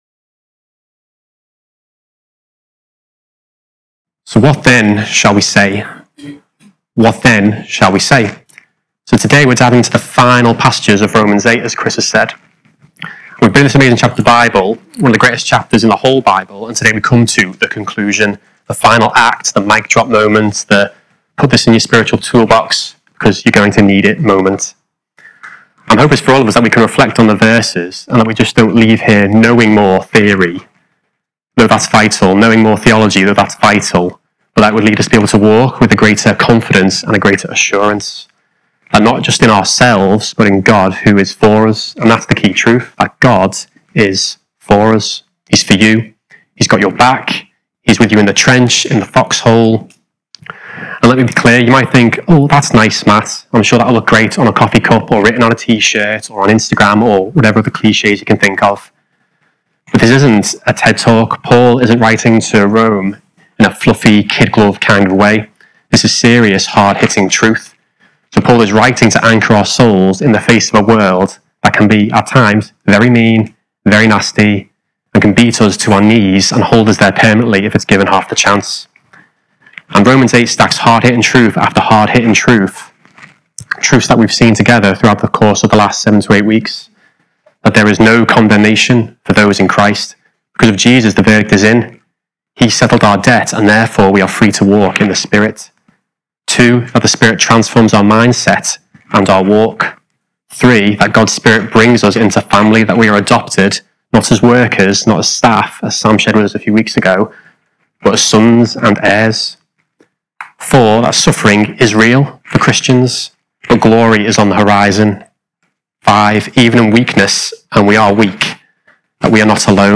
Cornerstone Wirral Sermon Podcast - What then shall we say?